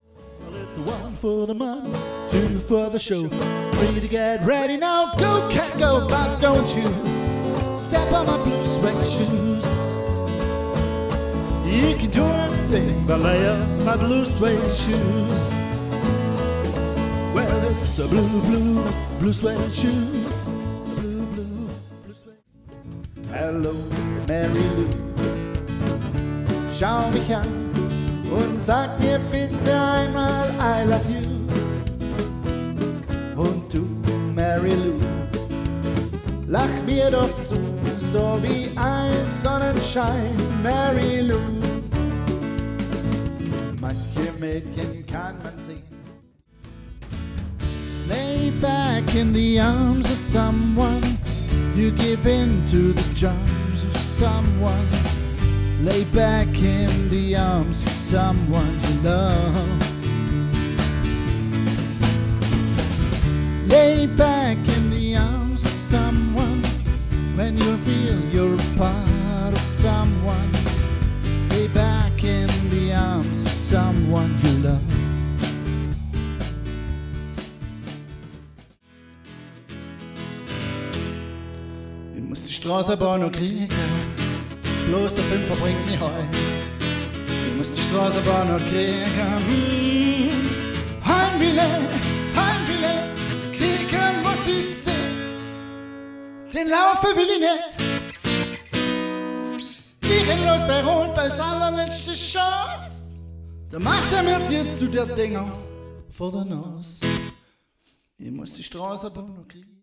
Unplugged - Pure Music